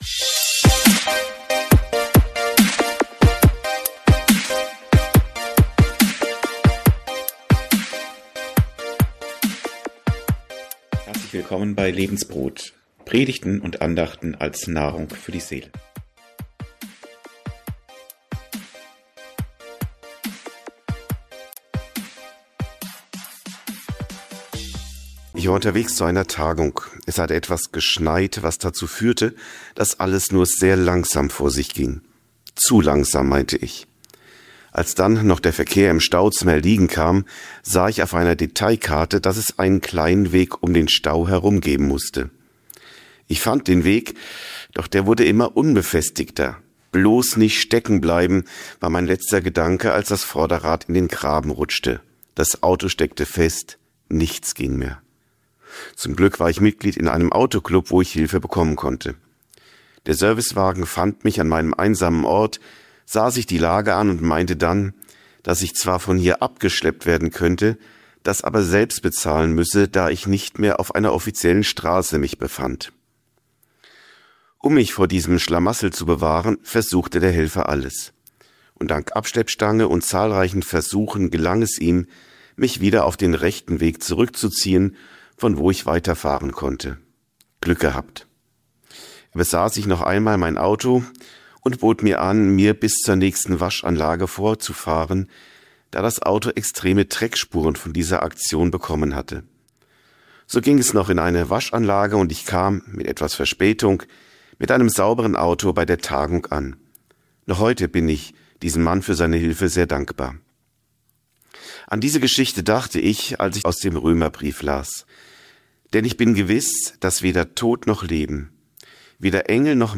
Andacht für ERF